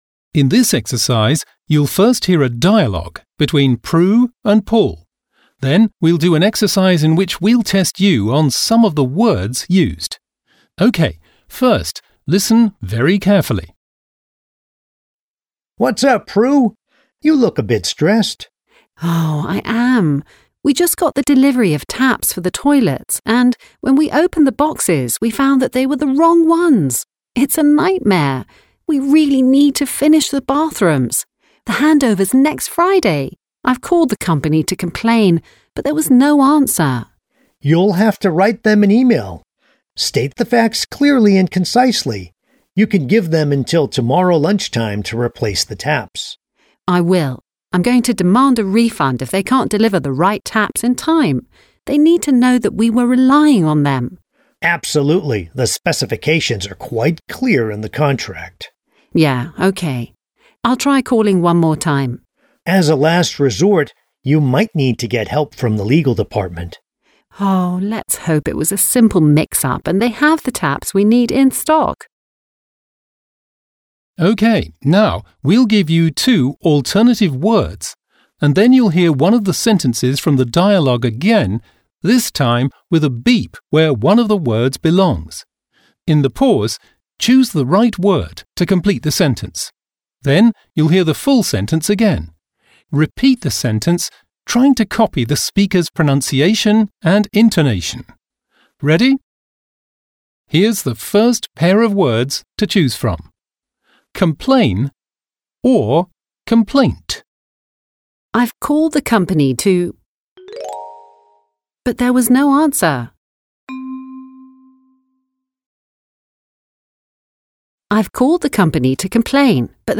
Audio-Trainer